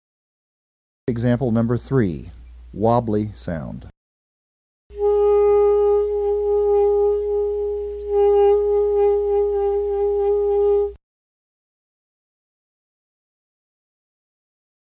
#3–WOBBLY SOUND
EmbSAX03.mp3